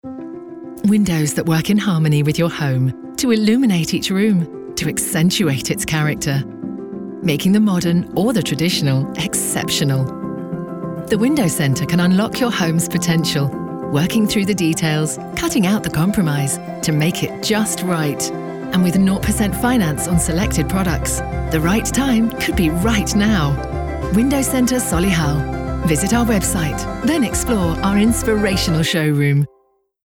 RADIO ADVERT V1
WC-Radio-Jan-2023.mp3